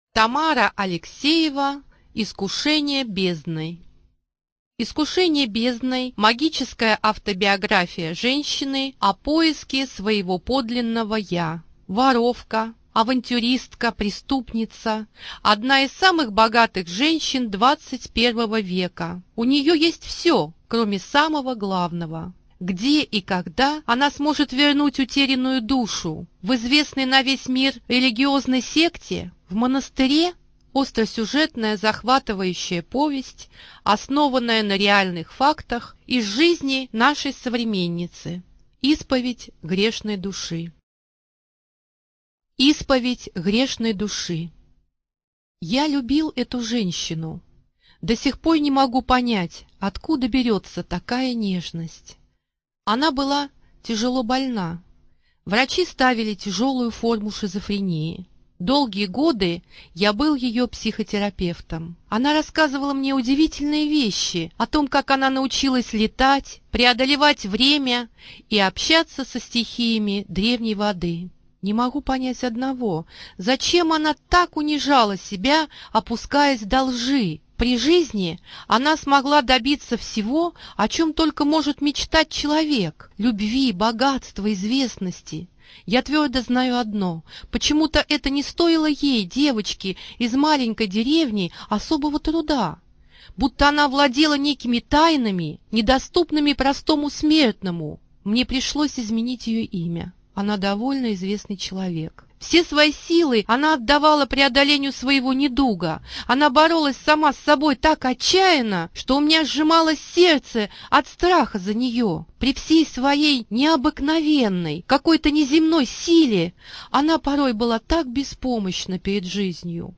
ЧитаетАвтор
ЖанрСовременная проза
Студия звукозаписиРавновесие